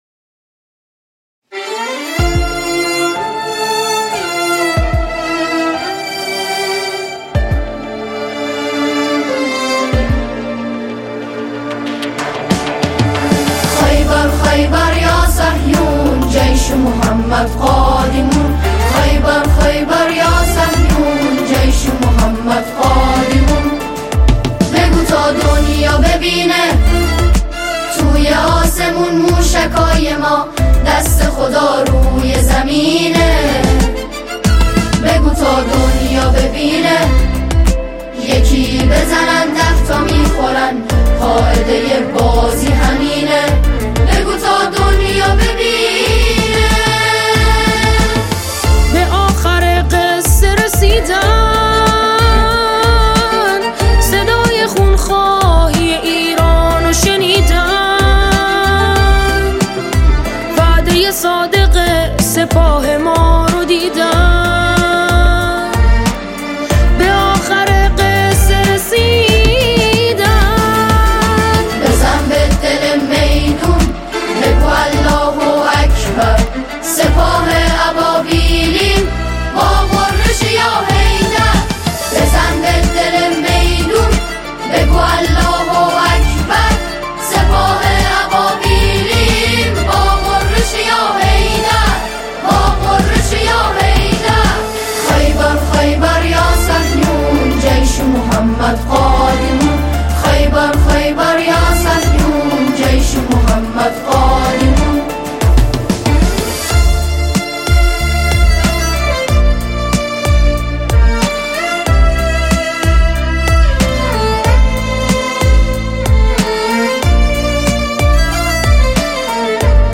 اثری حماسی و ملی‌ـ‌مذهبی